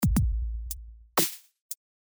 老学校
描述：基础，嘻哈丛林。
Tag: 120 bpm Hip Hop Loops Drum Loops 246.62 KB wav Key : Unknown